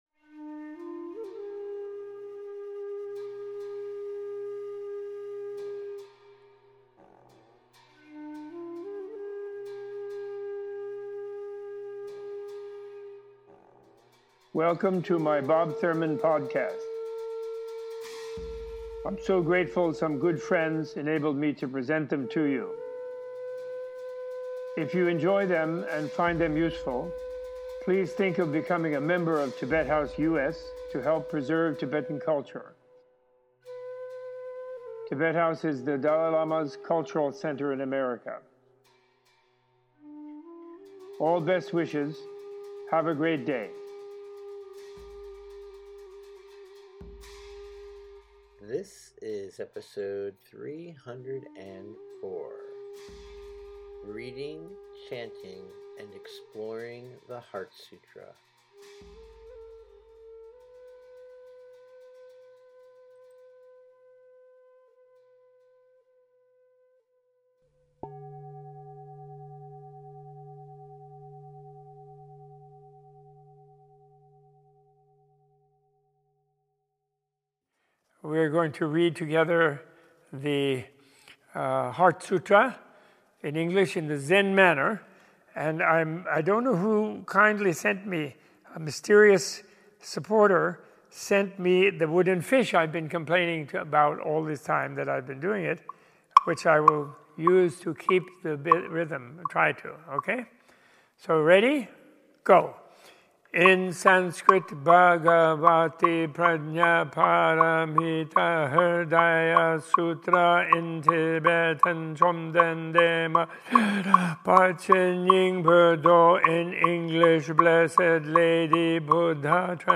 In this podcast Robert A.F. Thurman leads a recitation of The Heart Sutra and gives a teaching for all audiences on its connections to the Four Noble Truths and the Buddha's Eight Fold Path of liberation.